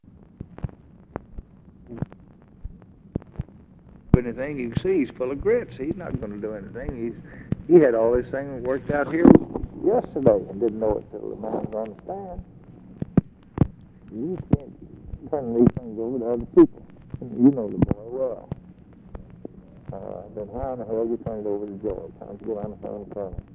OFFICE CONVERSATION, January 30, 1964
Secret White House Tapes